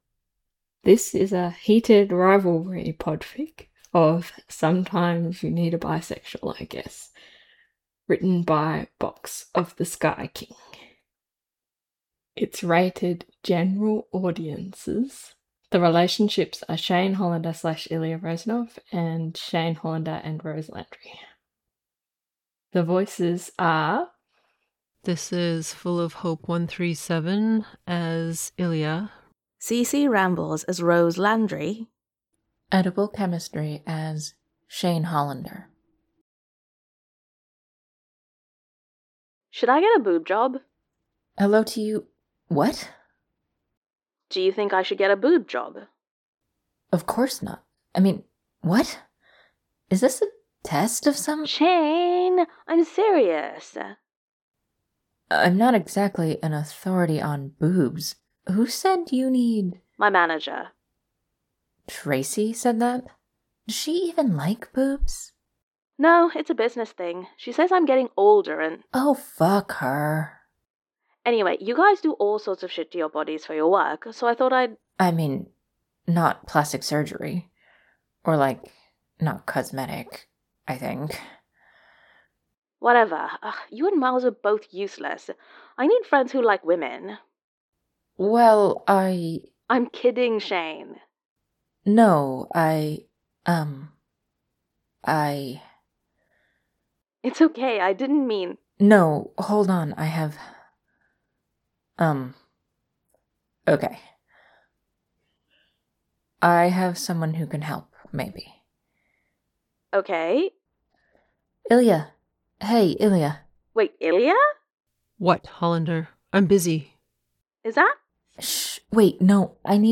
collaboration|ensemble